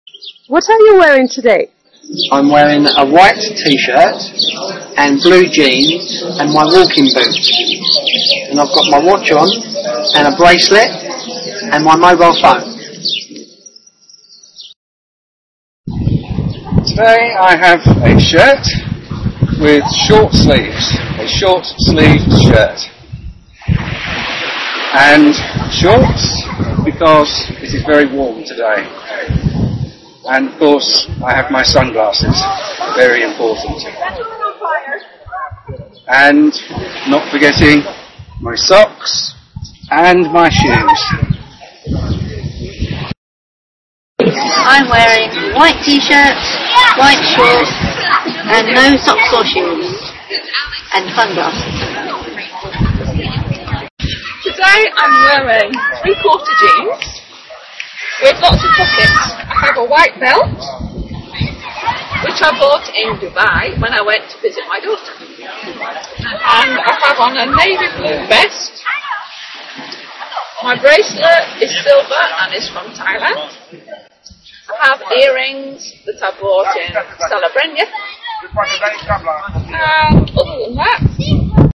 El video muestra a varias personas (dos hombres y dos mujeres) enseñando las distintas prendas y complementos que llevan (zapatos, pantalones, gafas, etc..).